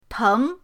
teng2.mp3